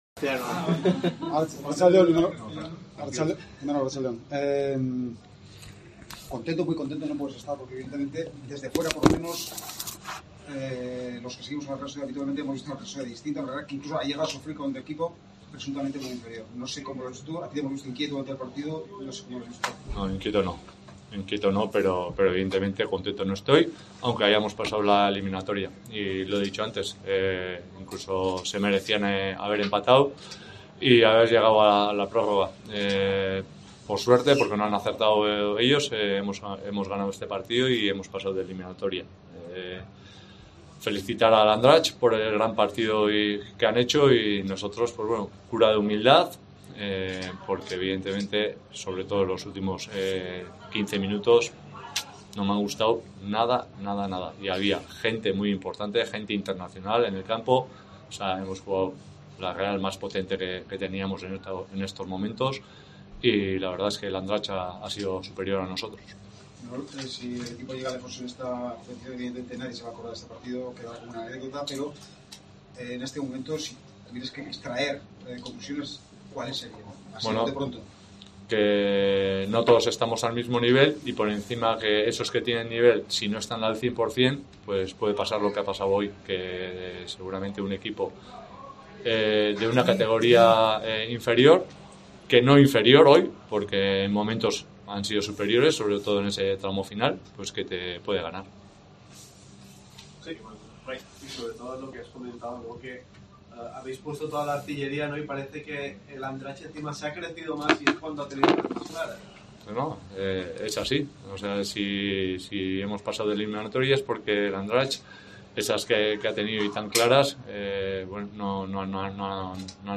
EN RUEDA DE PRENSA
Imanol Alguacil habló en la rueda de prensa post partido y se mostró muy descontento con el partido de los suyos.